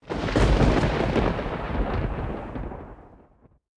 Thunder.ogg